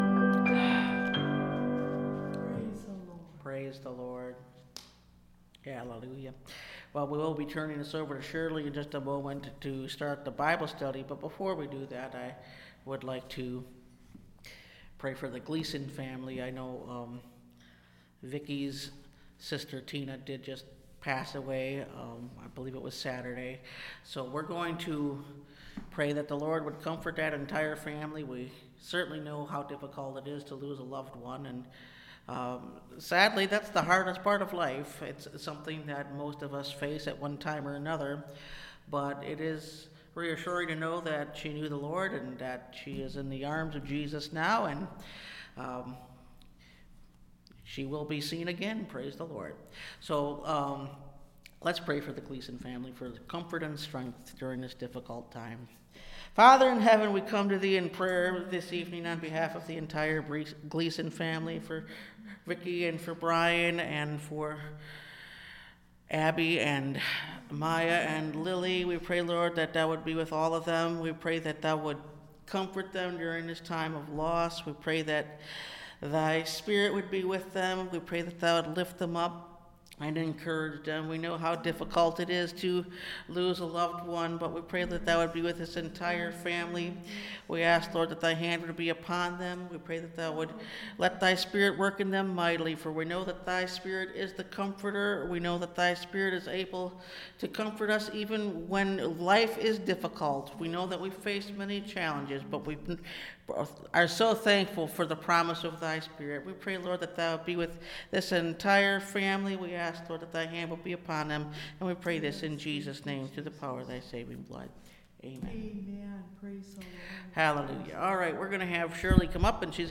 Perfecting Holiness (Message Audio) – Last Trumpet Ministries – Truth Tabernacle – Sermon Library